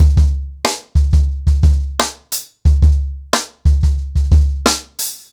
CornerBoy-90BPM.17.wav